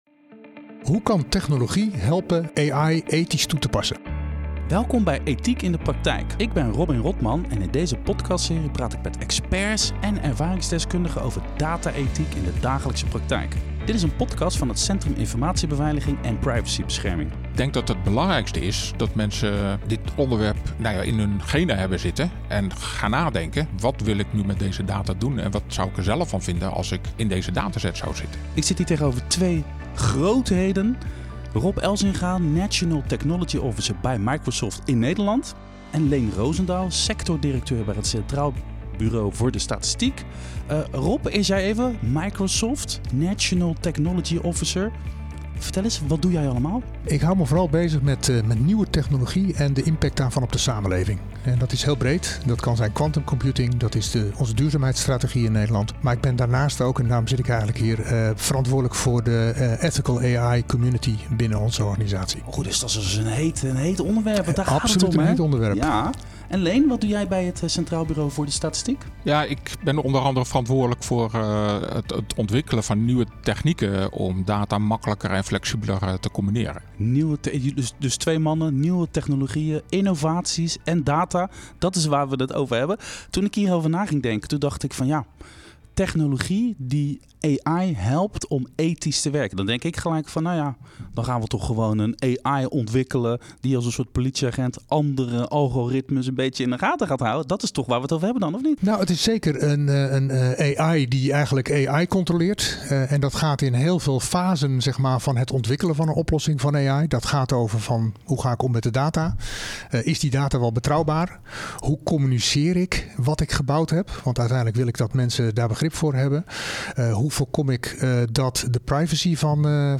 Twee mannen, nieuwe technologieën, innovaties en data, dat is waar we het over hebben.